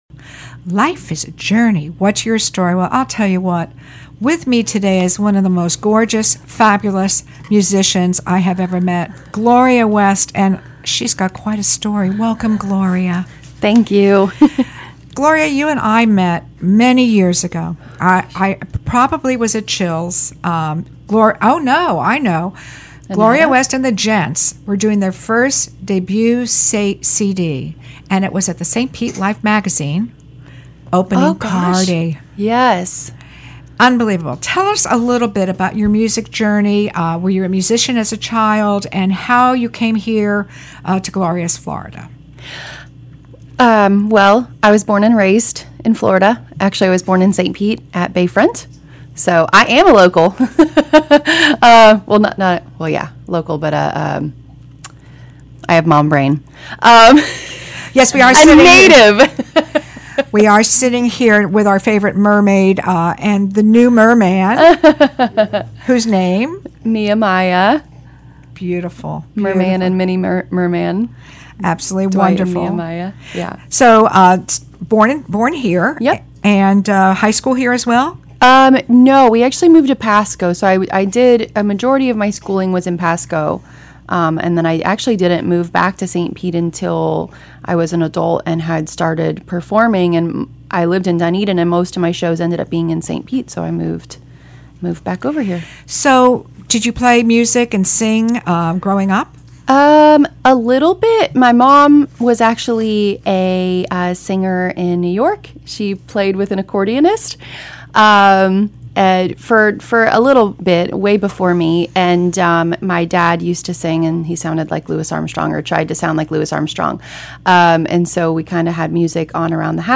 Her newest gents...hubby and baby joined us in studio for a catch-up chat.